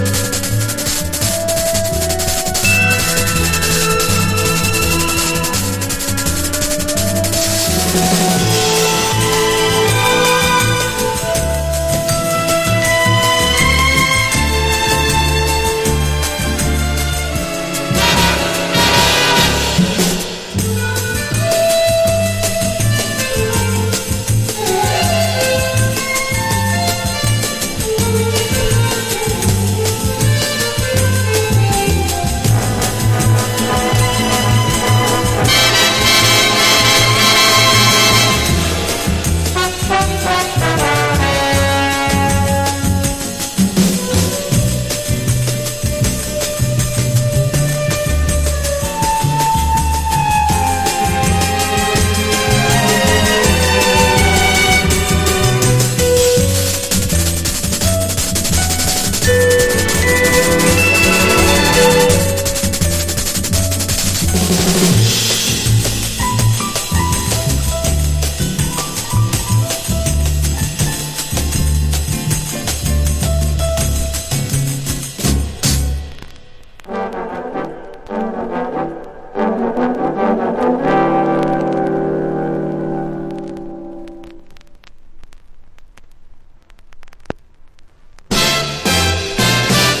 後半にハードバップな展開を見せるA2など、イイ感じにビートが打ってる楽曲が多く使えます。